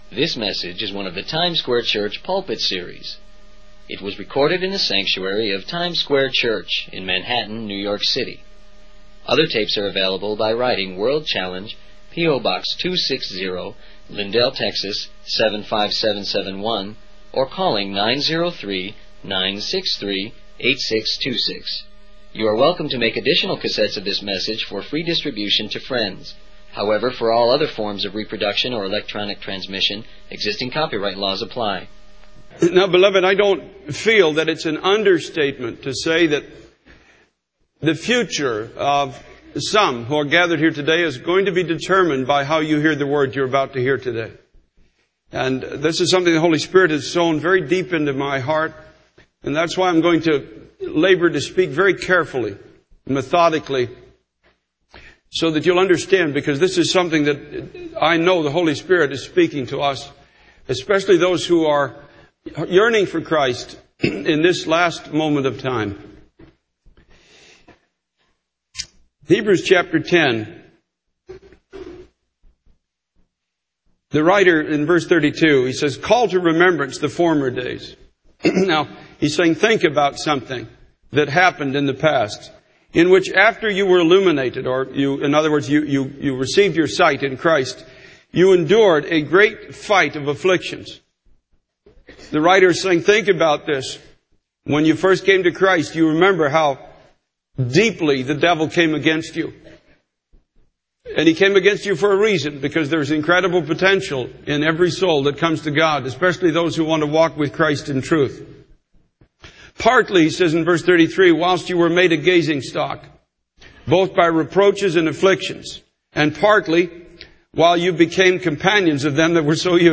In this sermon, the speaker emphasizes the importance of how we hear the word of God, stating that our future is determined by it.